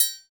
Index of /90_sSampleCDs/Roland - Rhythm Section/PRC_Latin 2/PRC_Triangles
PRC TRI1MUTE.wav